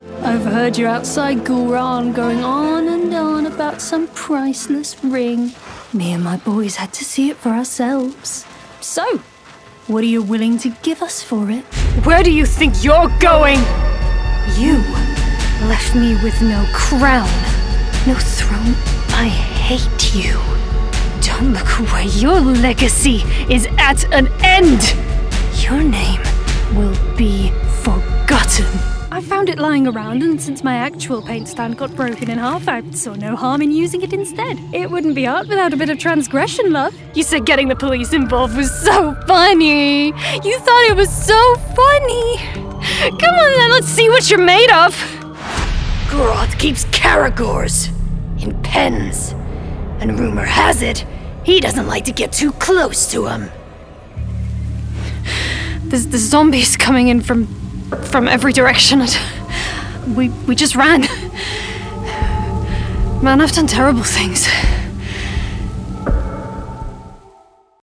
Video Game Demo